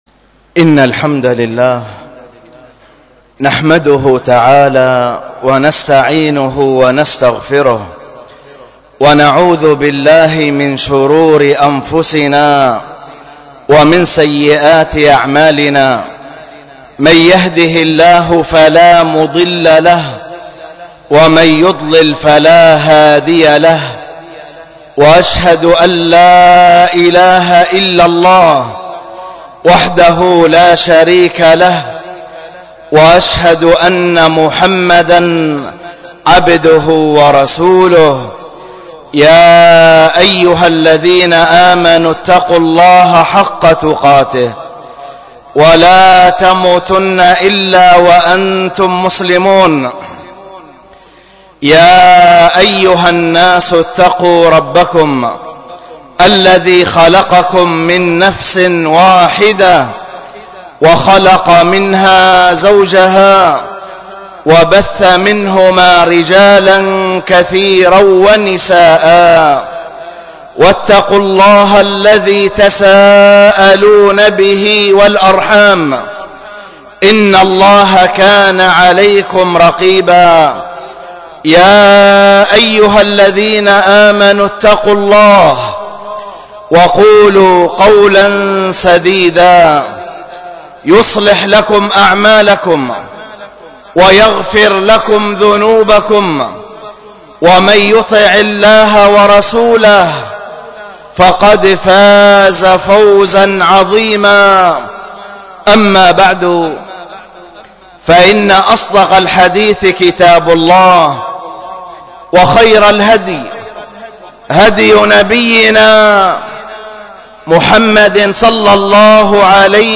الخطبة بعنوان التذكير بموت الفجأة، والتي كانت بمسجد التقوى بدار الحديث بالشحر